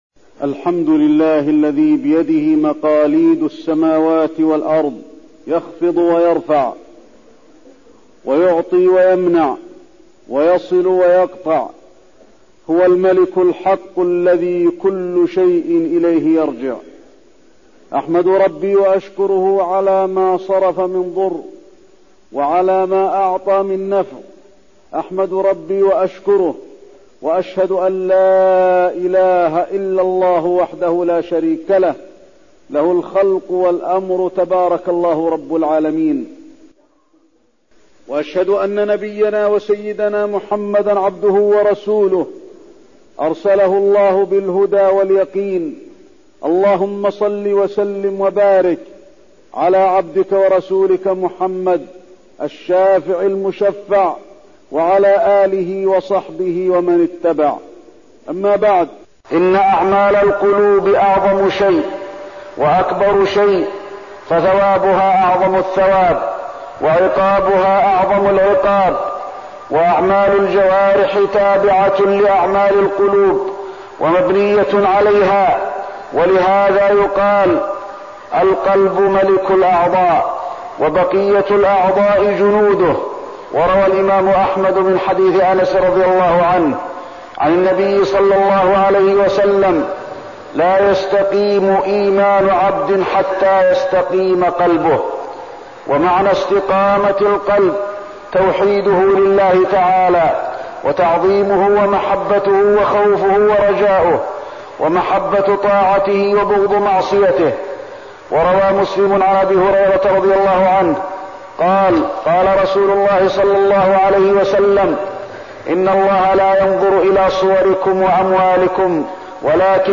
تاريخ النشر ١٣ شوال ١٤١٤ هـ المكان: المسجد النبوي الشيخ: فضيلة الشيخ د. علي بن عبدالرحمن الحذيفي فضيلة الشيخ د. علي بن عبدالرحمن الحذيفي الخوف والرجاء